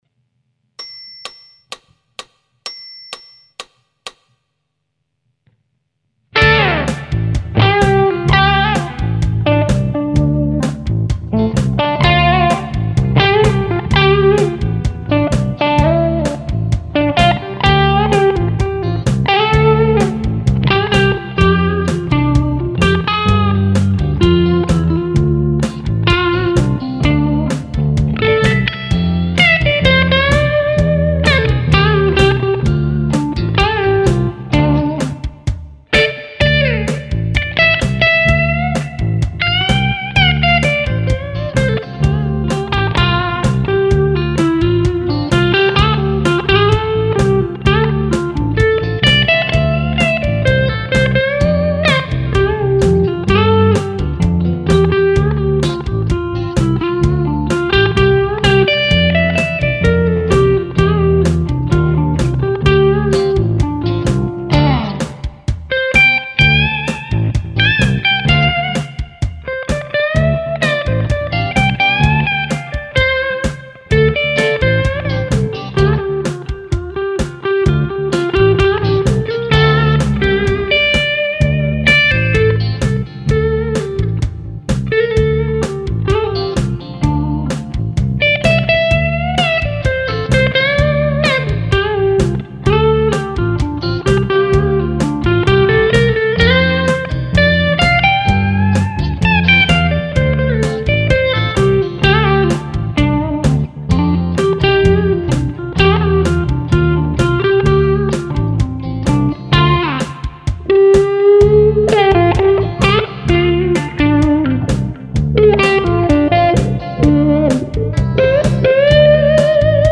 Als ich dann meinen Erfahrungsbericht zum Demonizer schrieb und zeigen wollte, daß der Demonizer auch in cleaneren Gefilden Beute machen kann, suchte ich ein passendes Backing und zufälligerweise wurde es halt dieses hier.
zweiter Take - mit Demonizer und Paula (und Colorsound-Wah)